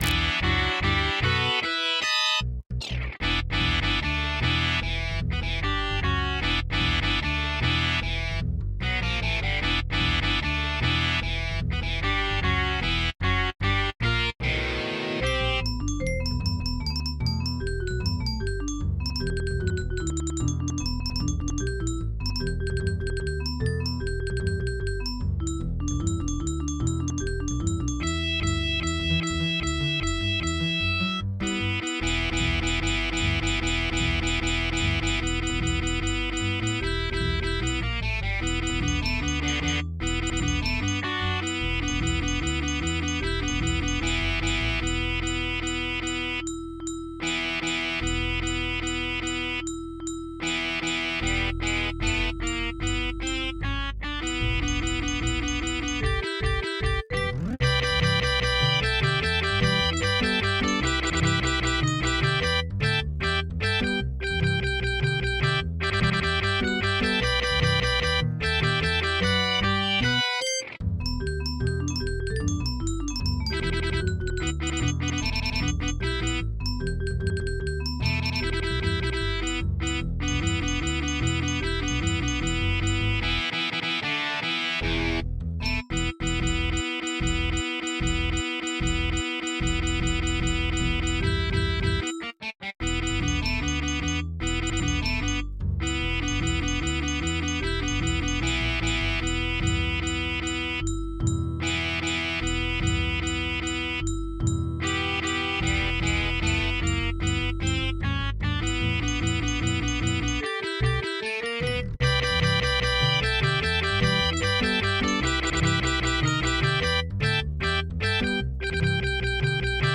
MIDI 28.59 KB MP3